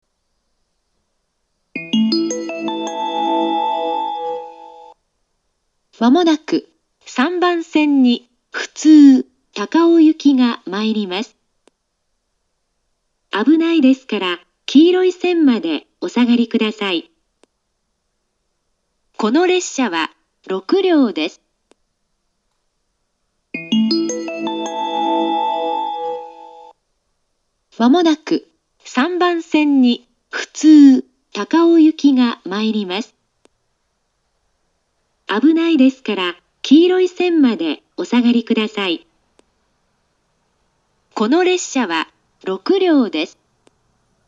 ３番線接近放送
上り本線です。